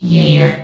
S.P.L.U.R.T-Station-13 / sound / vox_fem / year.ogg
CitadelStationBot df15bbe0f0 [MIRROR] New & Fixed AI VOX Sound Files ( #6003 ) ...
year.ogg